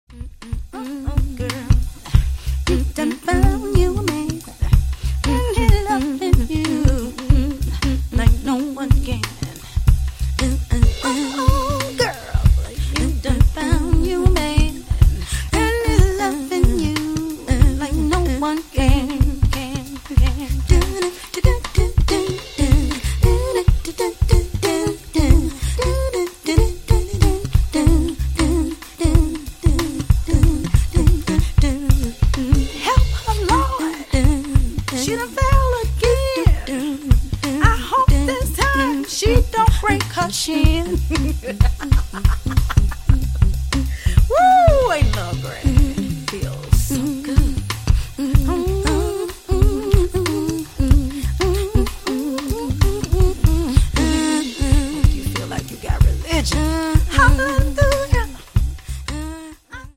with a strong voice
down tempo Soul tracks
good original ballads